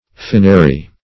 Finary \Fin"a*ry\
finary.mp3